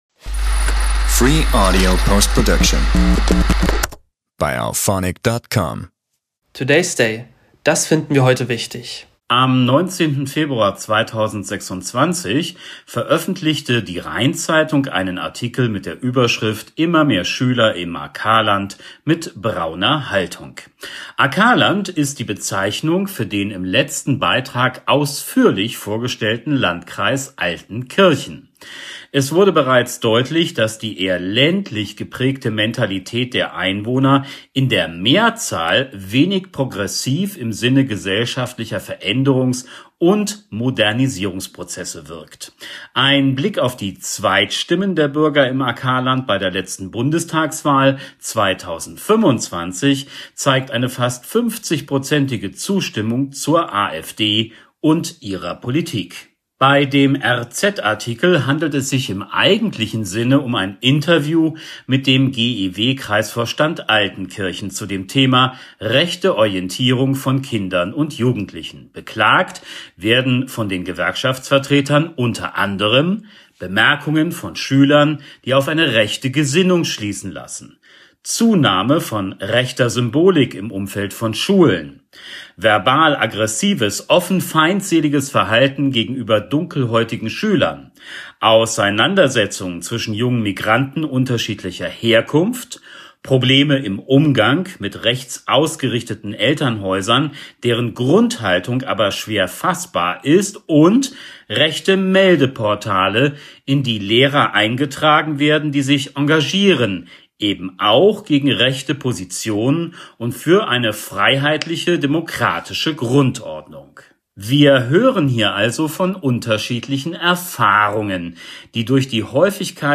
Ein weiterer Blick in das Interview mit dem GEW-Kreisvorstand